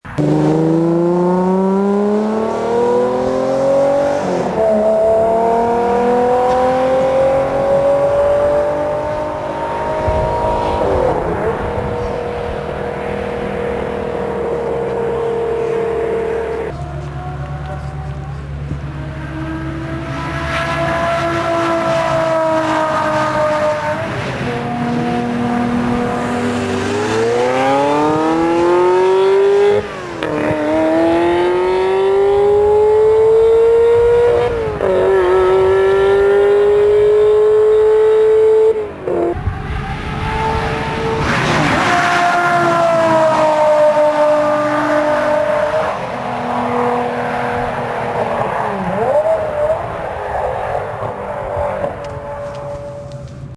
F50 and High Speed Pass (528 KB MP3 Format) Listen of the F50 taking off, then passing by at about 160 MPH, then you will hear the F355 starting off as another F50 passes... new
F50 pass long.mp3